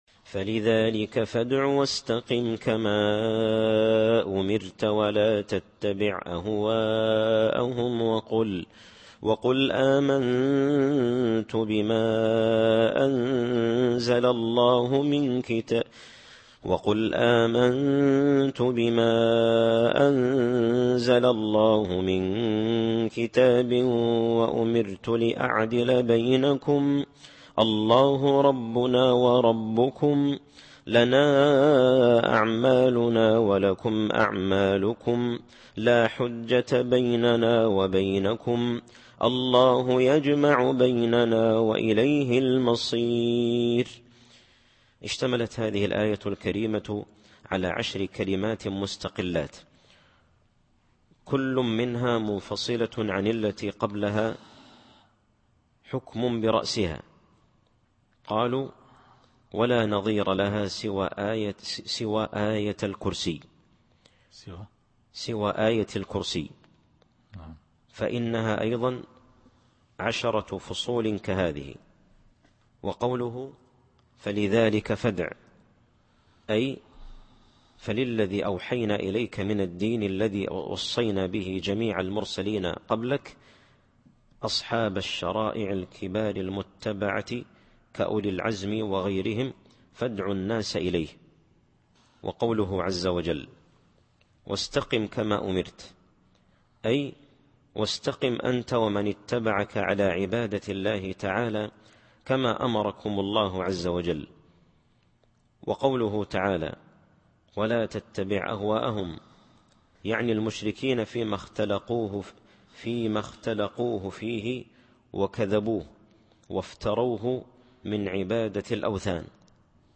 التفسير الصوتي [الشورى / 15]